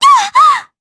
Laudia-Vox_Damage_jp_03.wav